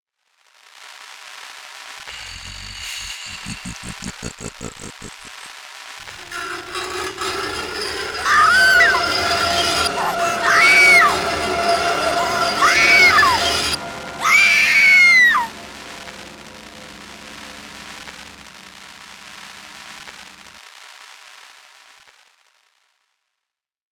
screams.wav